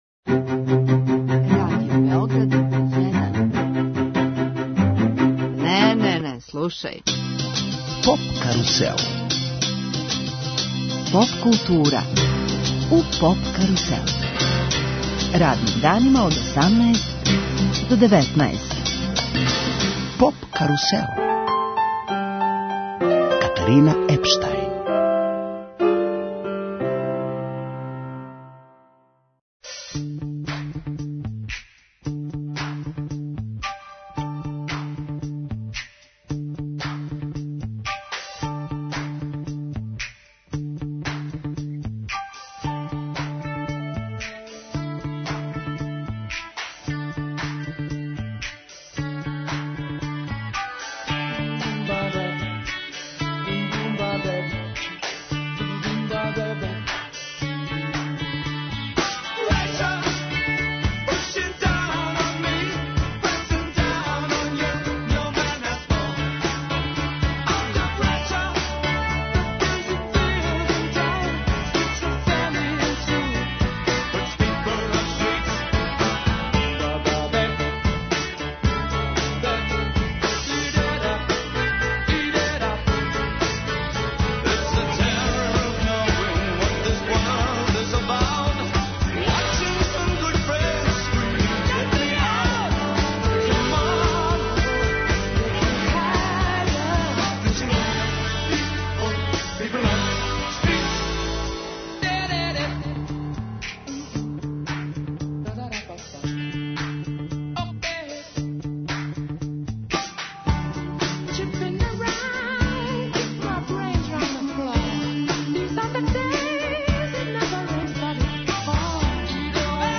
гитариста
певач
који уживо наступају у емисији.